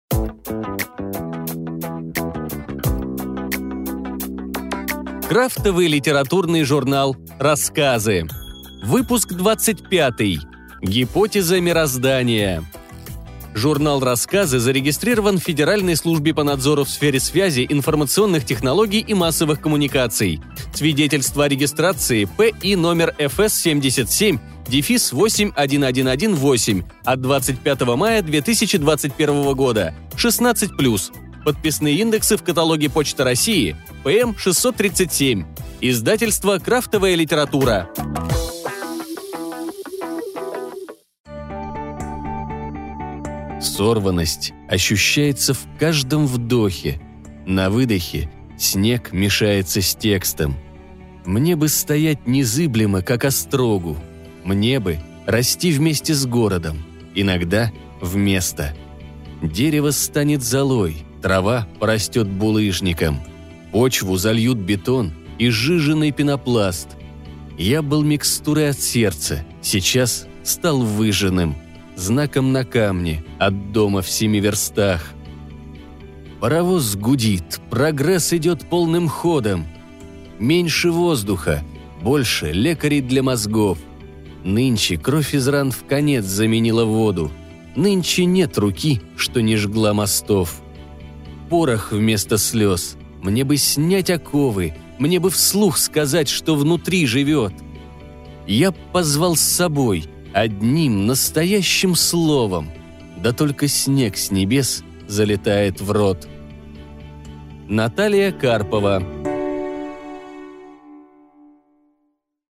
Аудиокнига Журнал Рассказы. Гипотеза мироздания | Библиотека аудиокниг